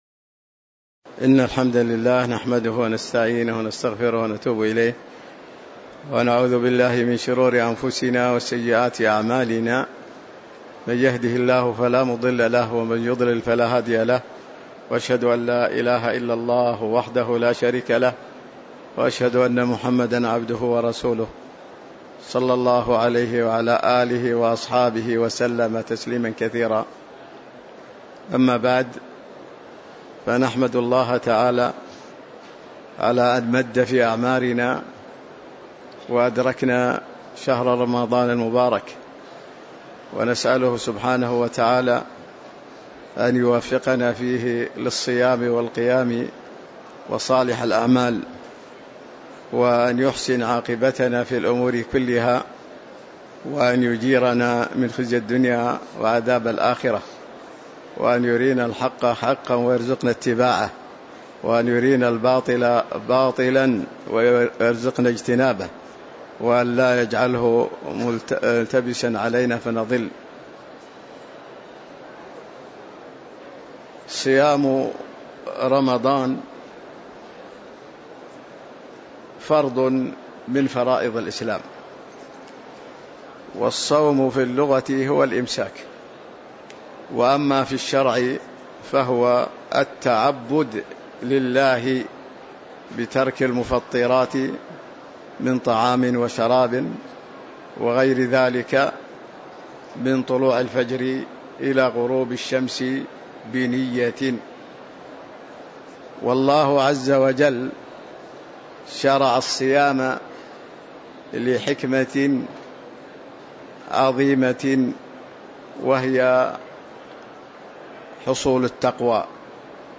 تاريخ النشر ١ رمضان ١٤٤٥ هـ المكان: المسجد النبوي الشيخ